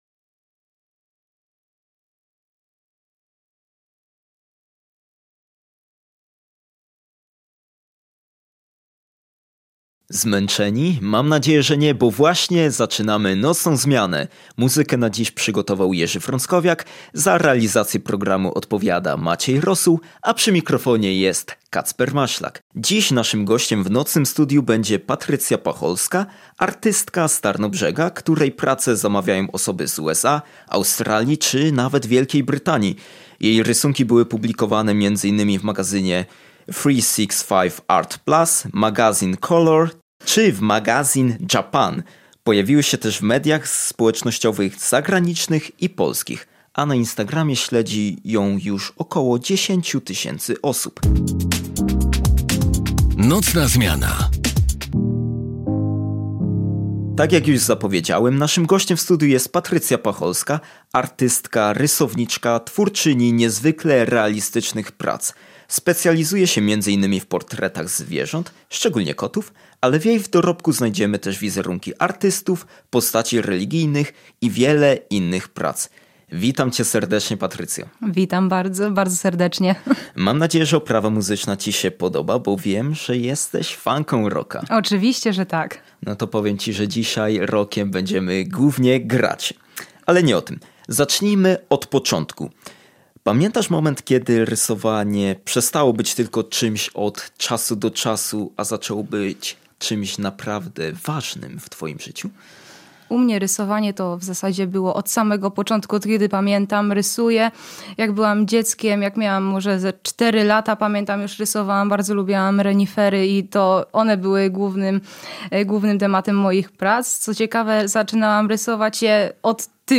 Podczas audycji opowiedziała o początkach swojej drogi artystycznej, procesie tworzenia oraz znaczeniu precyzji i cierpliwości w pracy nad każdym rysunkiem. Rozmowa dotyczyła zarówno jej doświadczeń, jak i dalszych planów związanych z rozwojem twórczości.